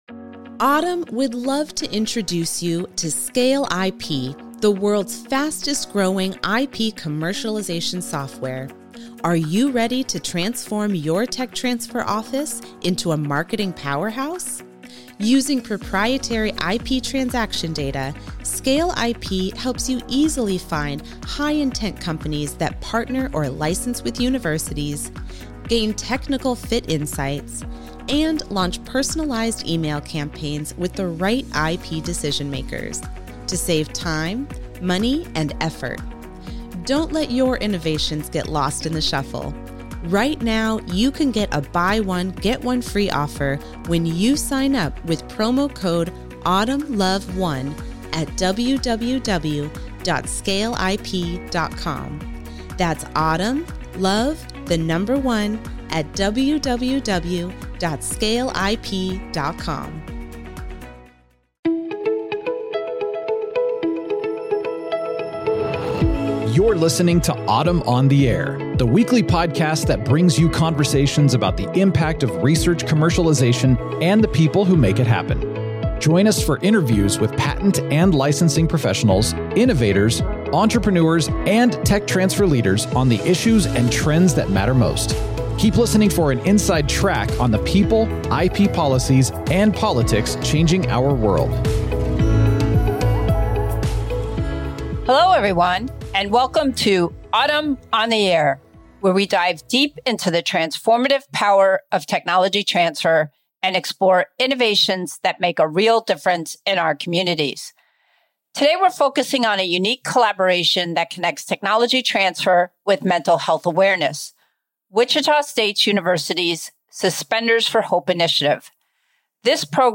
We’re joined by three incredible guests who helped make it happen.